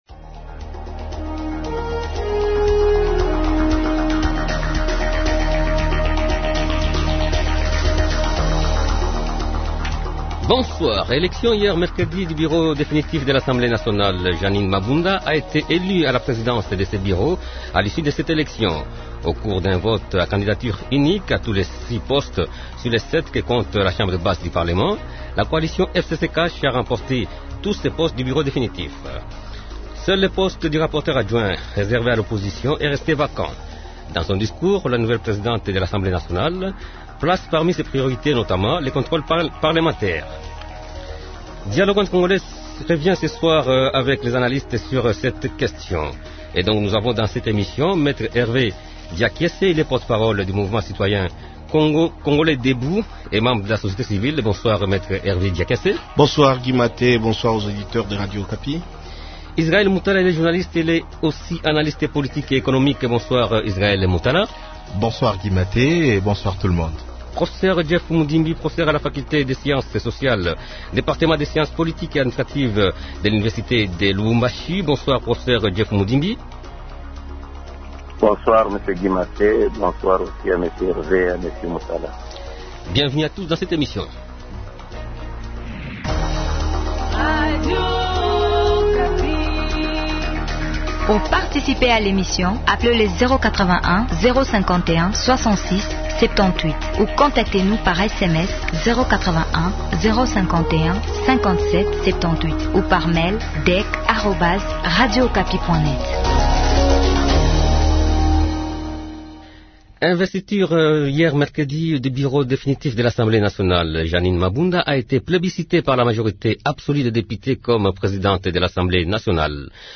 Dialogue entre congolais revient ce soir avec les analystes sur cette élection.